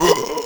c_zombim5_hit2.wav